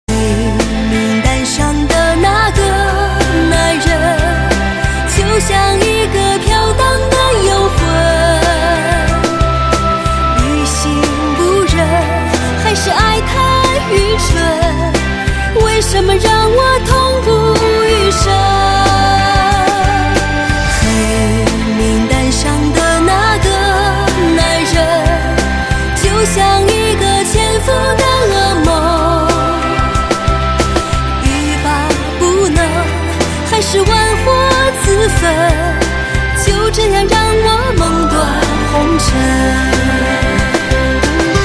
华语歌曲